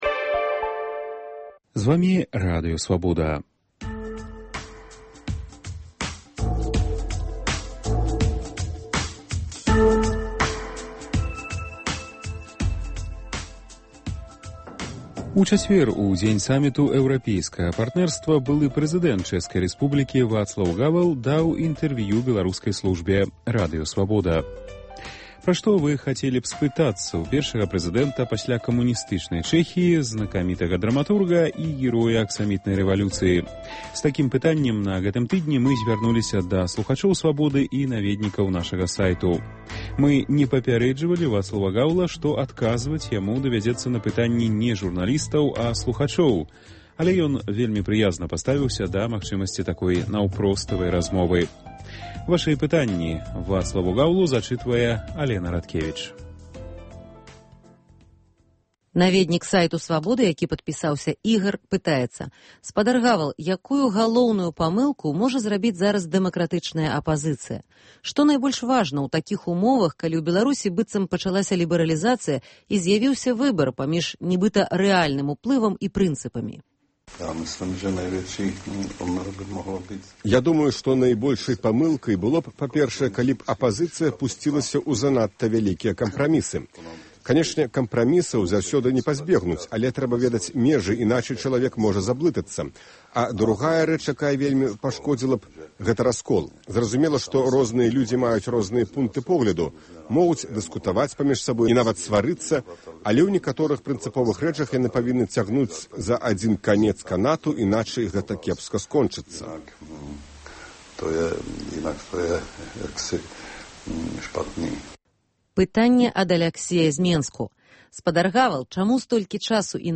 Першы прэзыдэнт Чэхіі Вацлаў Гавал адказвае на пытаньні наведнікаў нашага сайту, слухачоў і журналістаў Радыё Свабода.